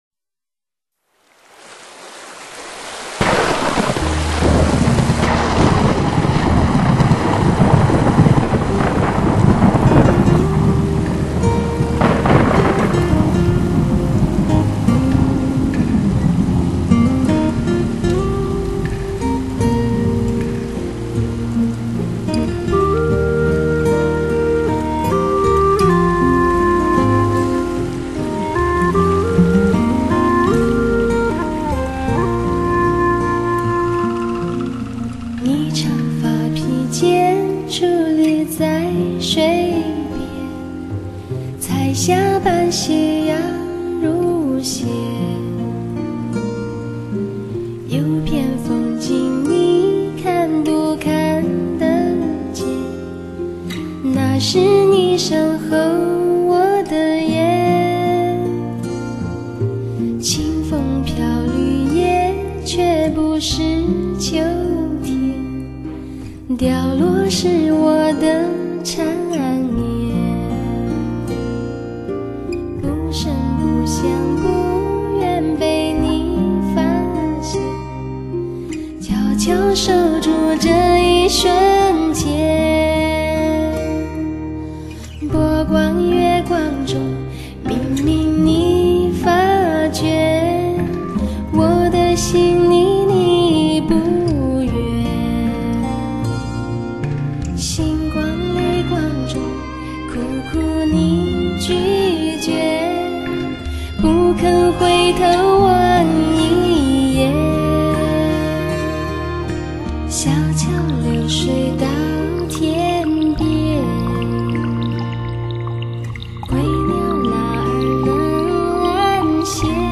最具灵气的原生态曲风，蕴藏着大自然的原始精华；清新纯美，体会那独属彝族神秘与传奇的感悟。
一个用歌声赞誉生活的彝家“精灵”，一次最具灵气的原生态曲风演绎，一场自然之美、精神之美真实体现。